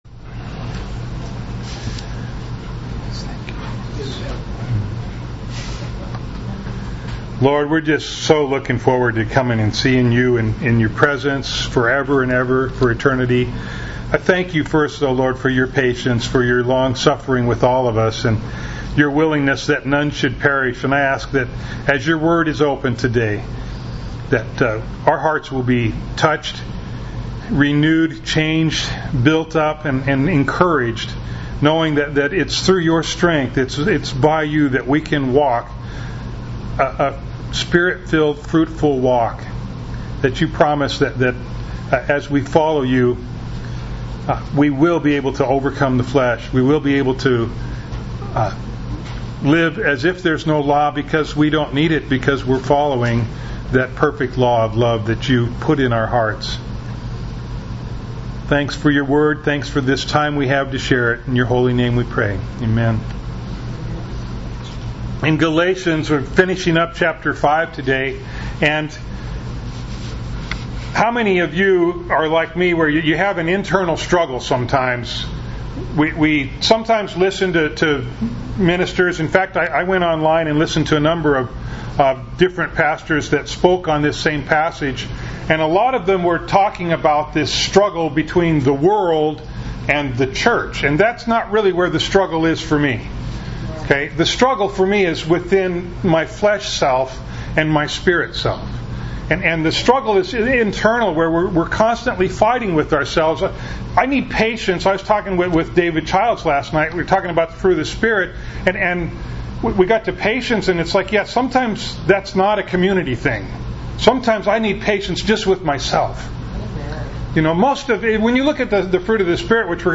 Bible Text: Galatians 5:16-26 | Preacher